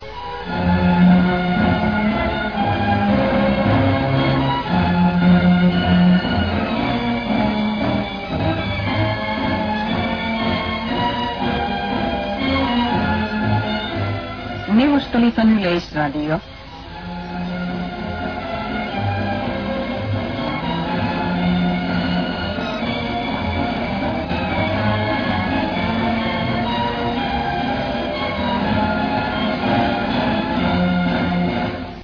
sign-on tune